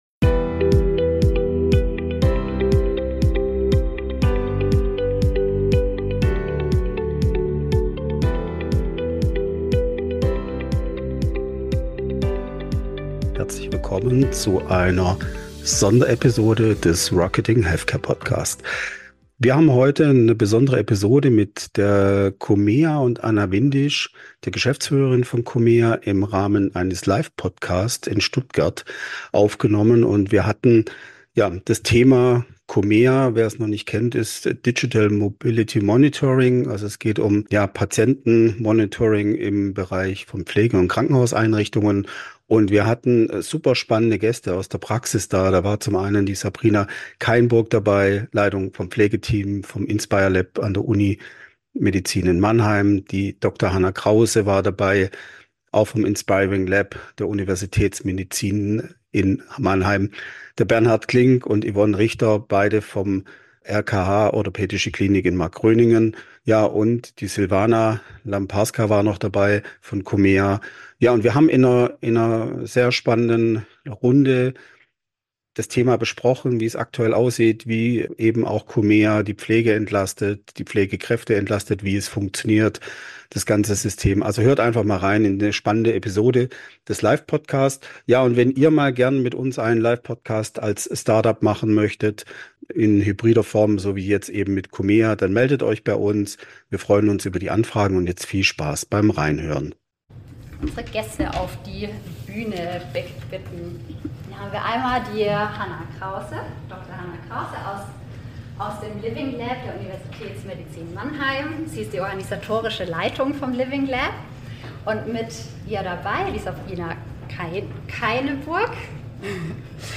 Pflegekräfte, Klinikleitung und Startup im Gespräch über digitale Entlastung, Patientensicherheit und die Realität von Innovation im Krankenhaus.